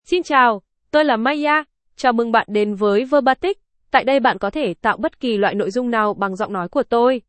Maya — Female Vietnamese (Vietnam) AI Voice | TTS, Voice Cloning & Video | Verbatik AI
MayaFemale Vietnamese AI voice
Maya is a female AI voice for Vietnamese (Vietnam).
Voice sample
Listen to Maya's female Vietnamese voice.
Maya delivers clear pronunciation with authentic Vietnam Vietnamese intonation, making your content sound professionally produced.